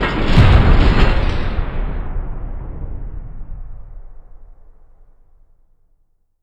LC IMP SLAM 3.WAV